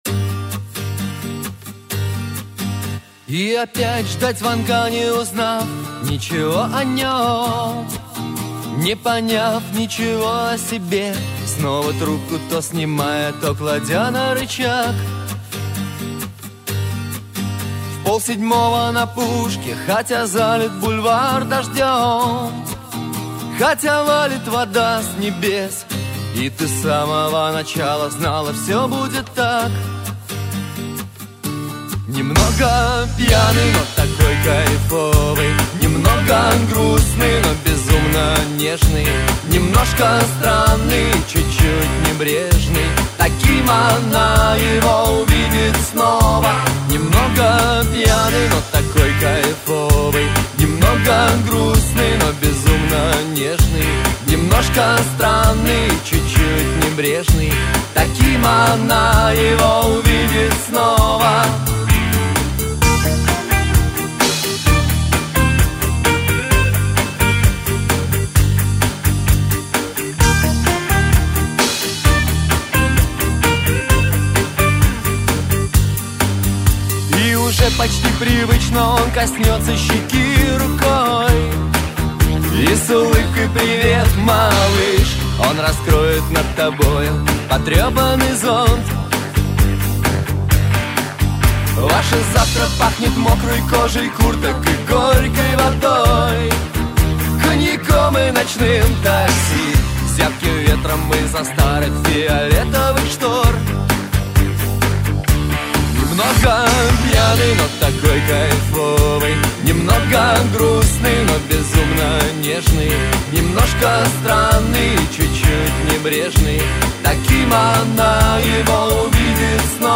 Категория: Шансон песни
шансон музыка